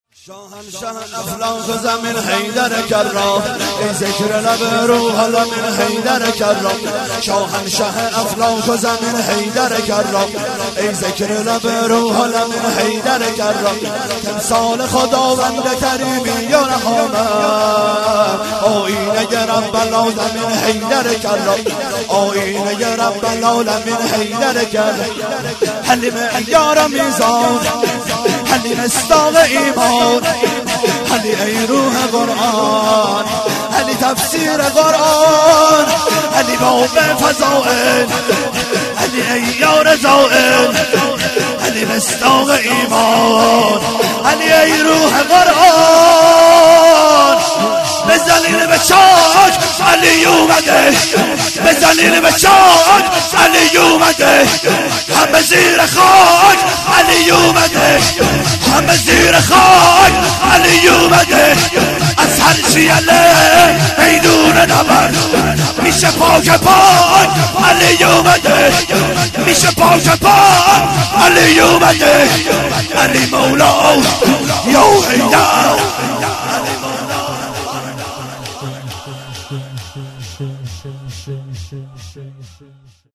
جشن ولادت حضرت زینب(س)- جمعه 29 دیماه